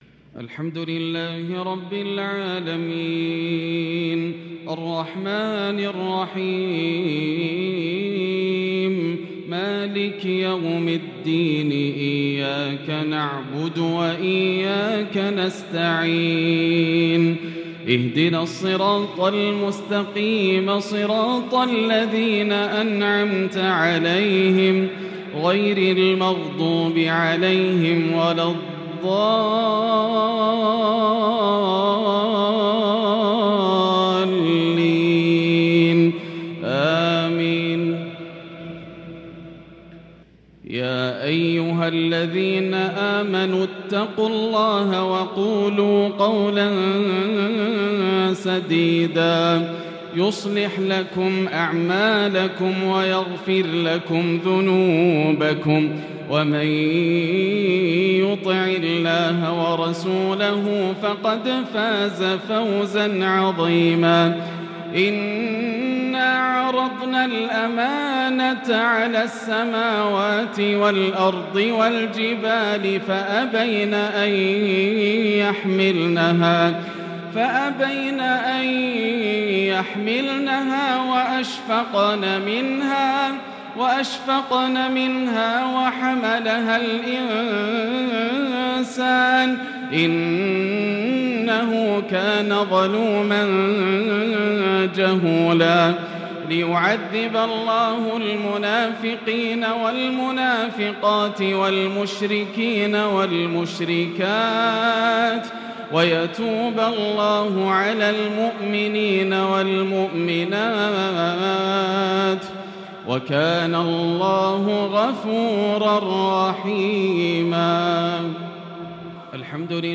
من سورتي الأحزاب و القمر | Maghrib Prayer from Surat Al-Ahzab and Al-Qamar 8-6-2022 > 1443 🕋 > الفروض - تلاوات الحرمين